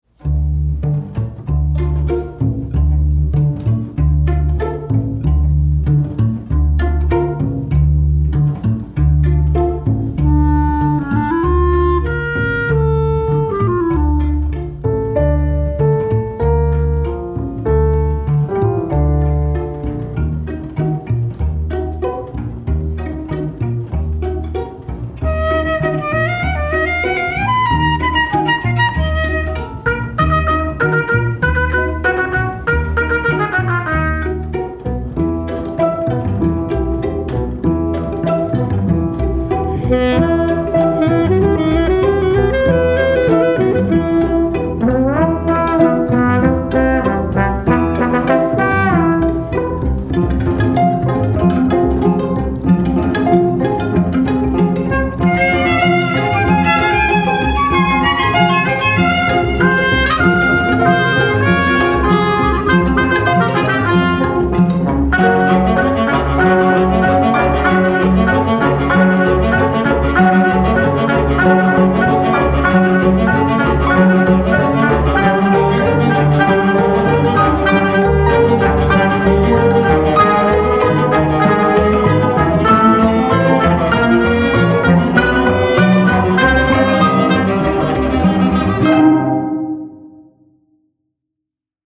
a delightful romp